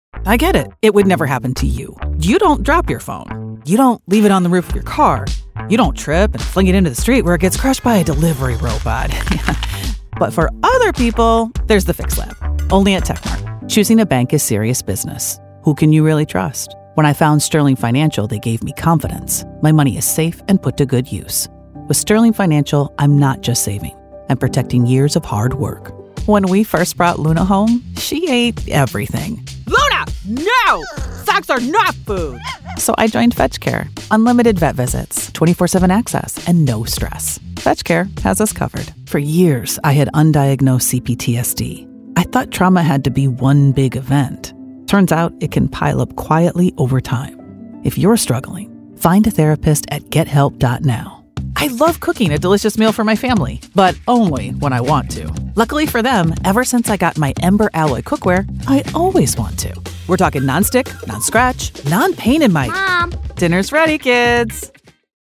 VOICEOVER ACTOR
Commercial Demo